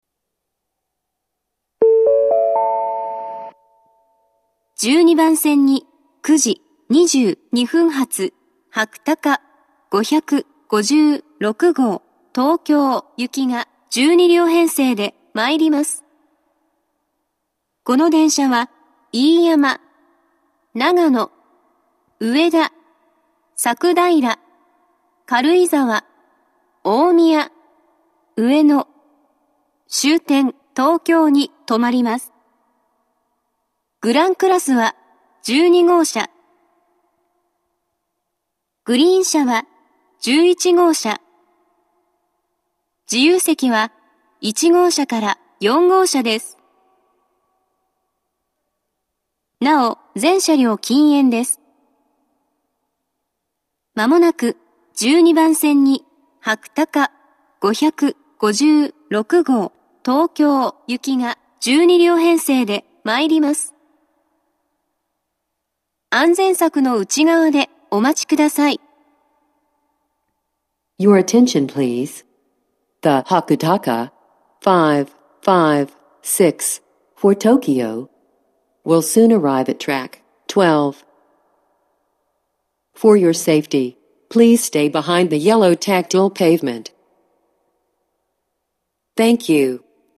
１２番線接近放送